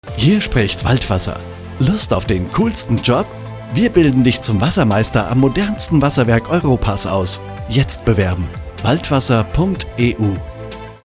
Funkspot_Wasserversorgung-Bayerischer-Wald-Koerperschaft-d.-oe.-Rechts-11-Sek.mp3